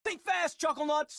fireworks sounds